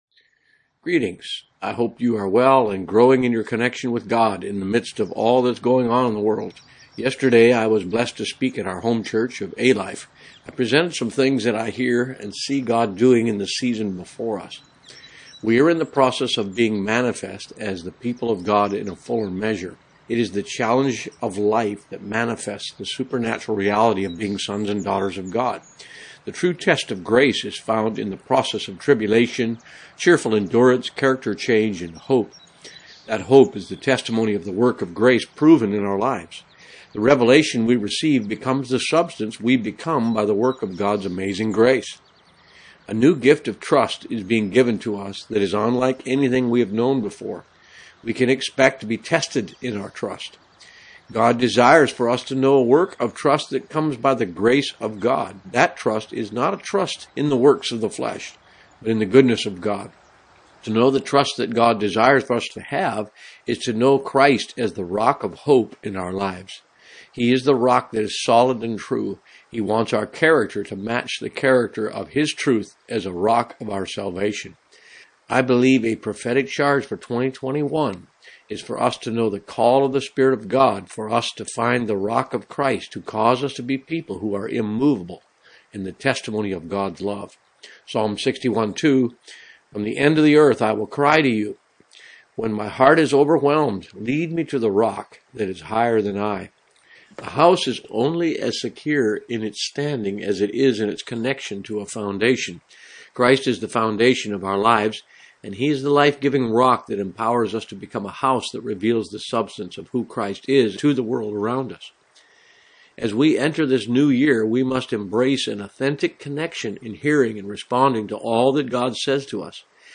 Blog In Audio: